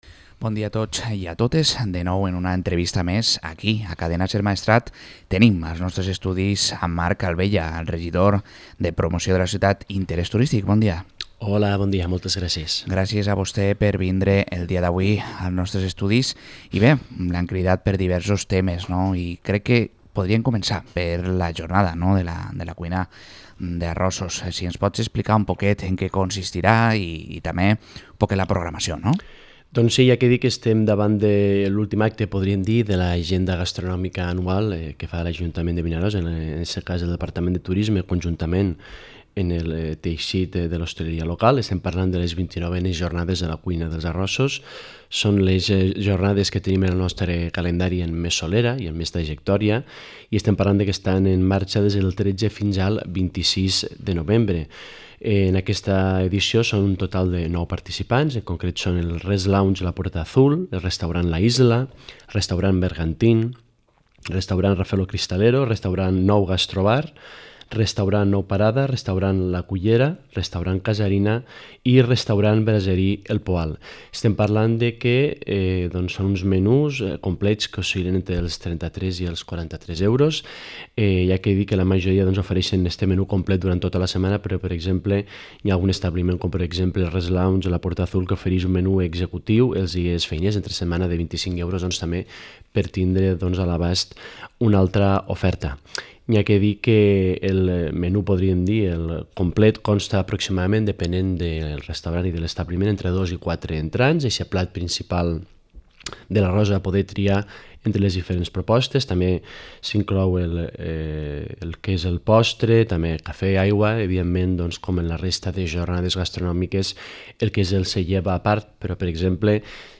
Entrevista a Marc Albella, regidor de Promoció de la ciutat i Interés turístic de Vinaròs